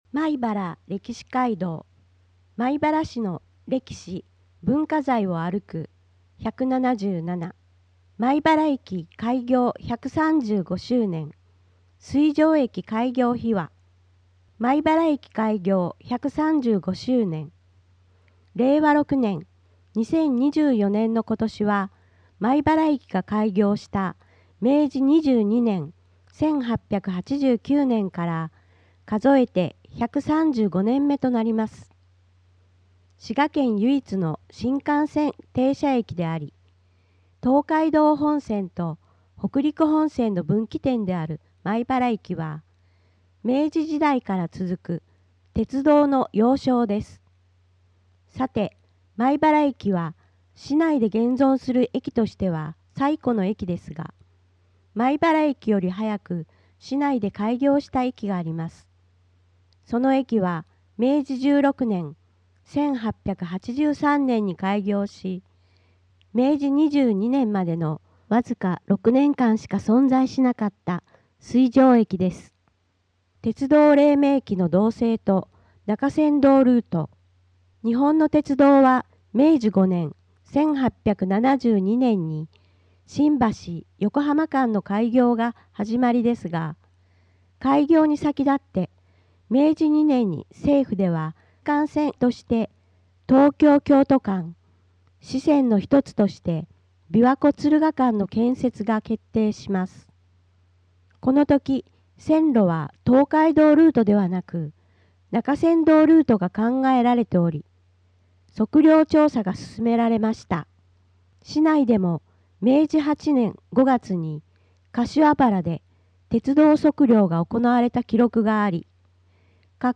障がい者用に広報まいばらを音訳した音声データを掲載しています。音声データは音訳グループのみなさんにご協力いただき作成しています。